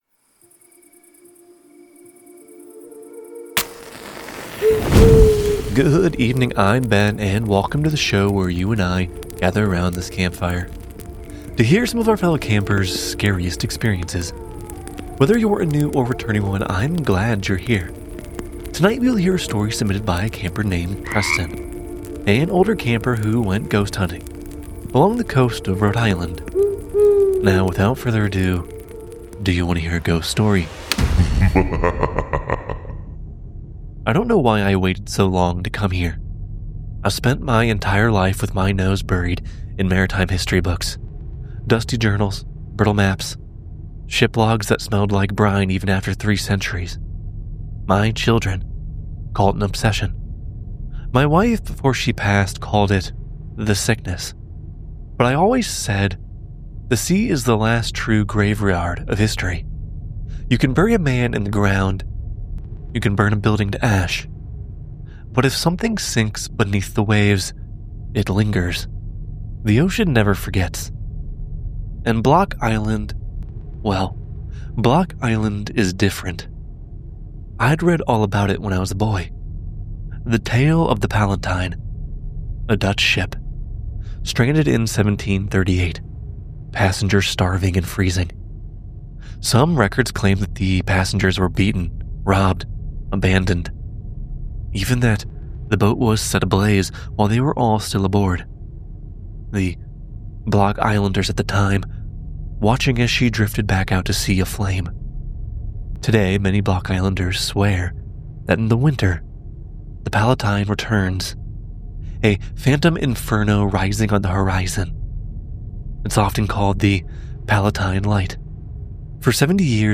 Narrated by:
Sound Design by: